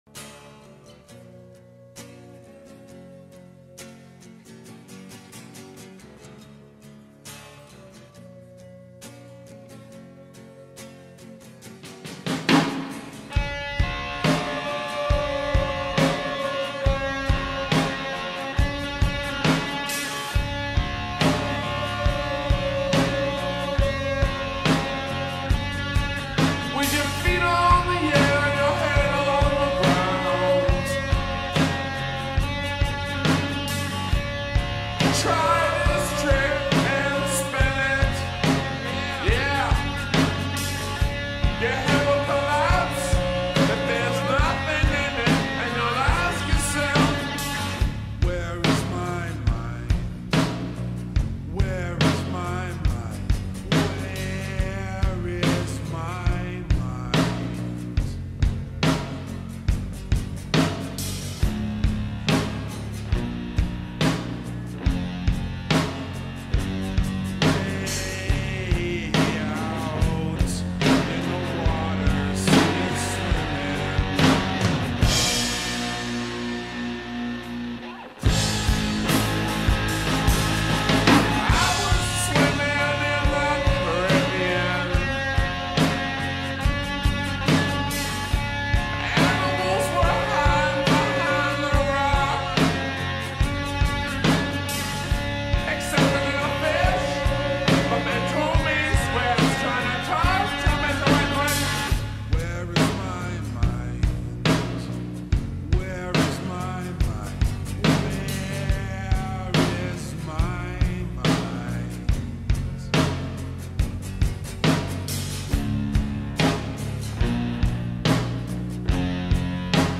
آهنگ های خارجی
غمگین